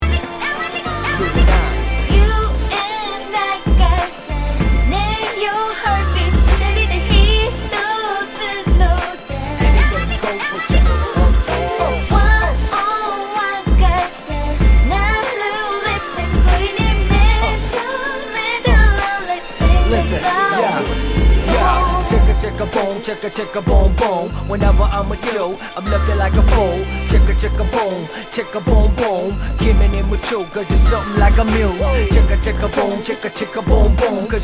Tag       Japan R&B